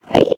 Minecraft Version Minecraft Version 25w18a Latest Release | Latest Snapshot 25w18a / assets / minecraft / sounds / mob / endermen / idle1.ogg Compare With Compare With Latest Release | Latest Snapshot